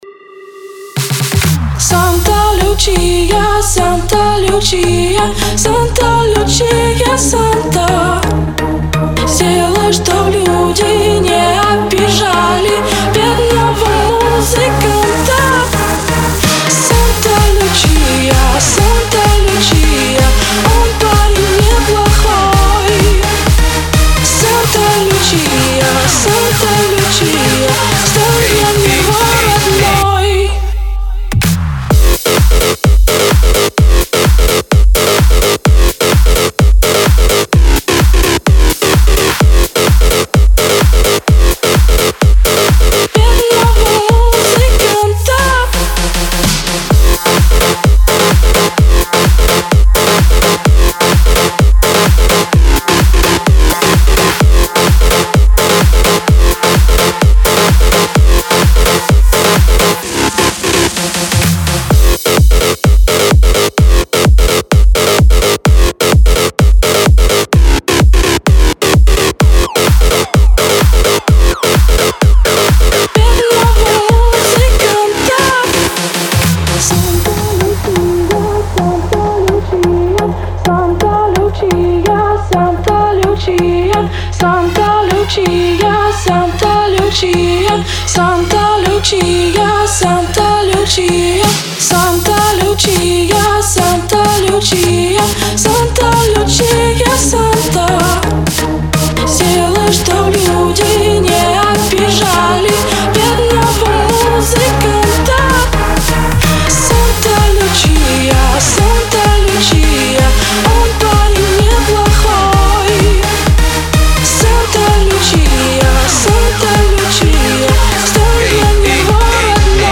Категория: Ремиксы